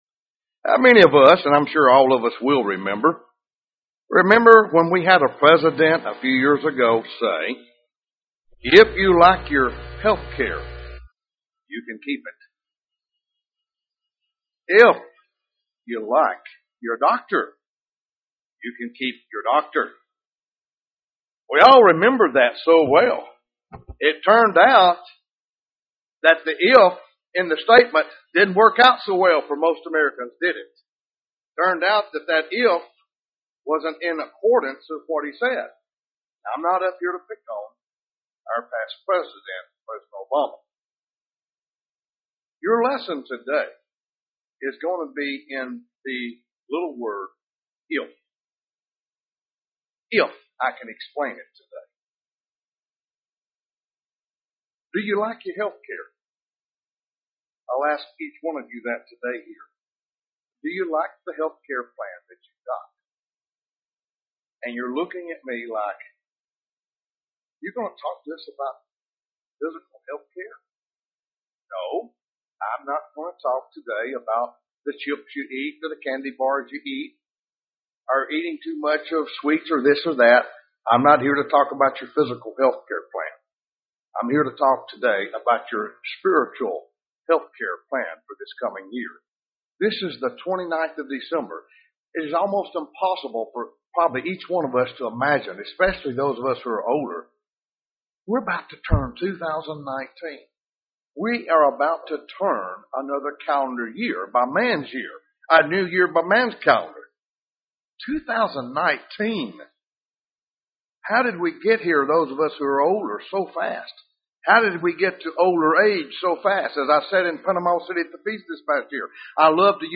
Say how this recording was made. Given in Birmingham, AL Huntsville, AL